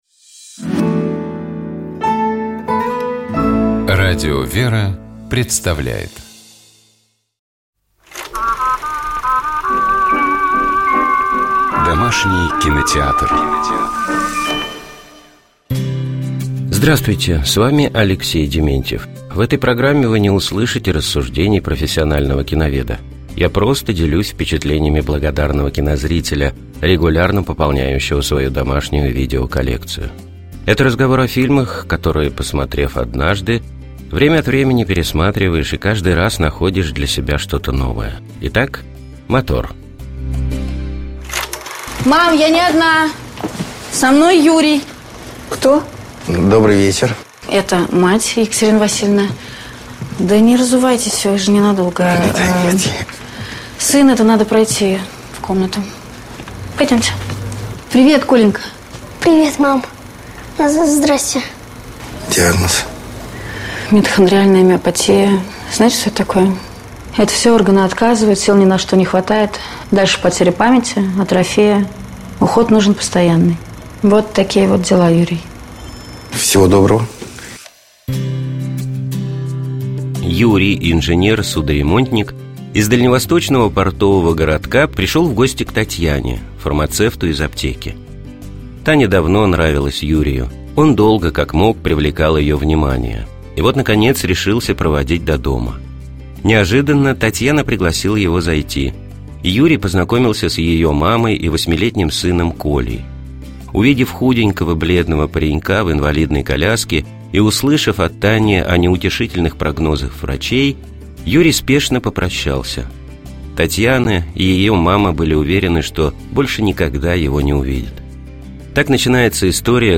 С вами епископ Переславский и Угличский Феоктист.